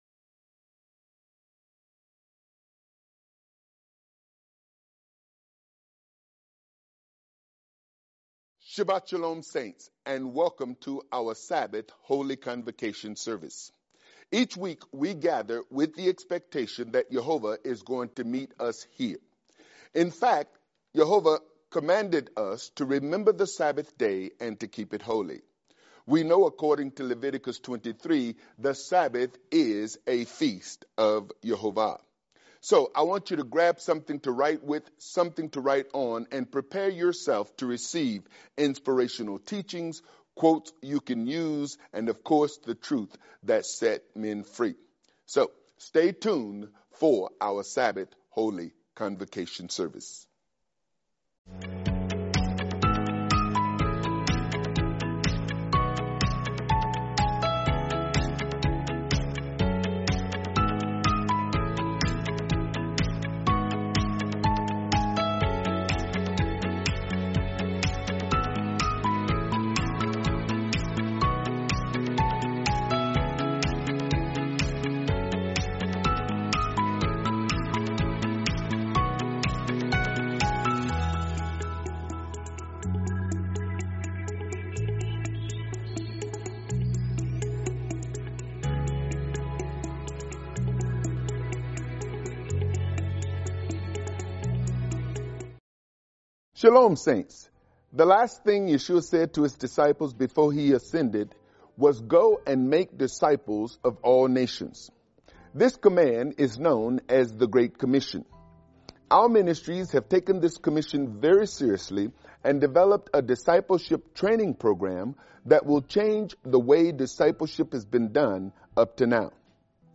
The Cost of Truth | Teaching